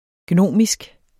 Udtale [ ˈgnʌmisg ]